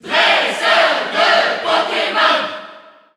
File:Pokémon Trainer Male Cheer French SSBU.ogg
Pokémon_Trainer_Male_Cheer_French_SSBU.ogg